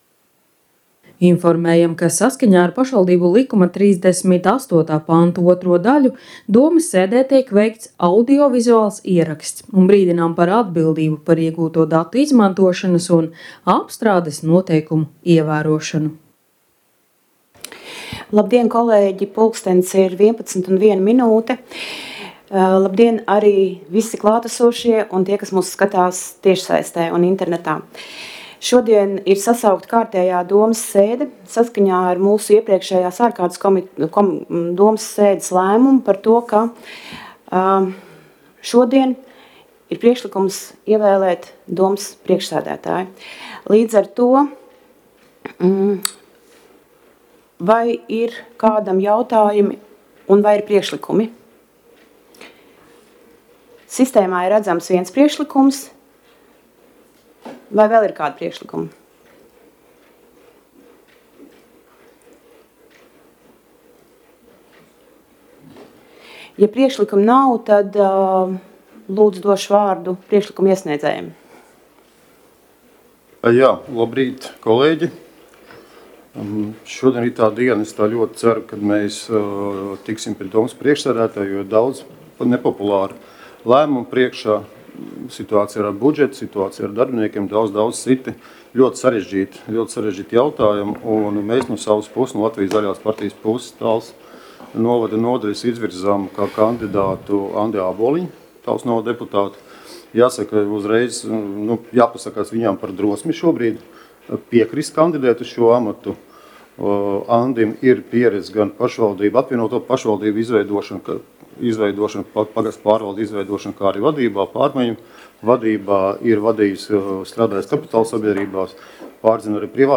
Domes sēdes audio